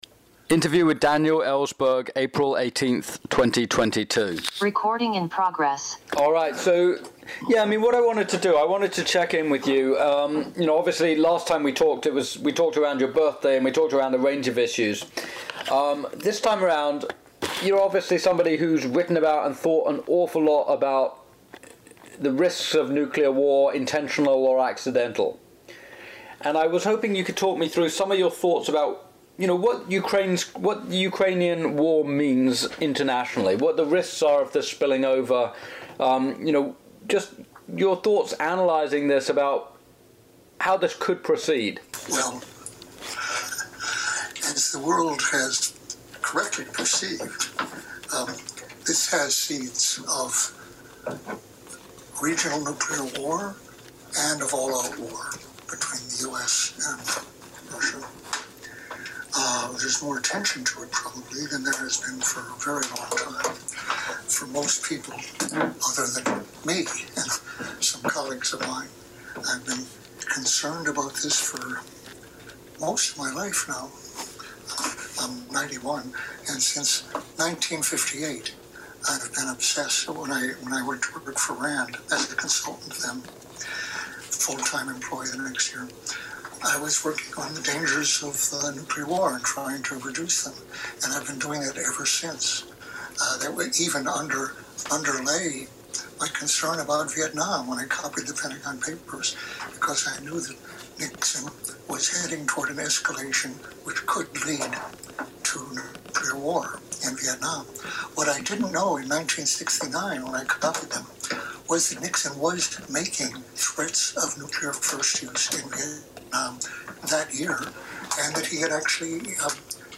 Listen to the full audio of our interview with Daniel Ellsberg above.